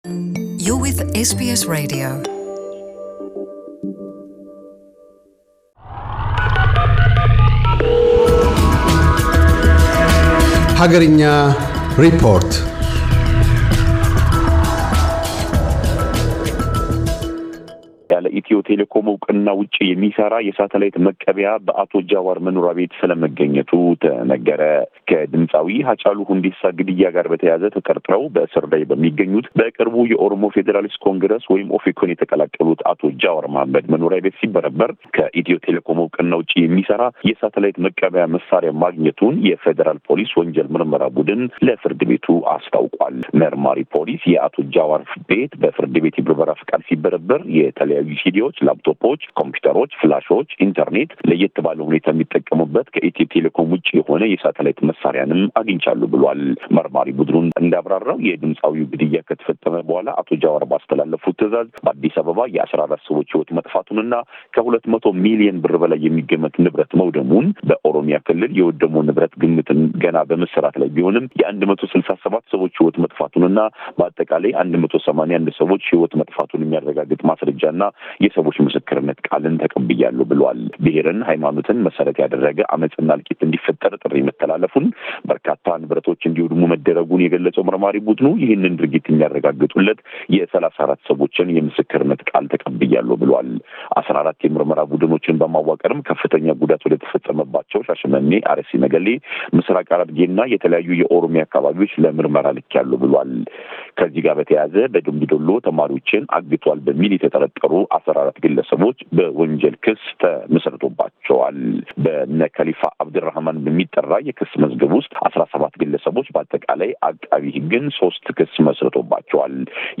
አገርኛ ሪፖርት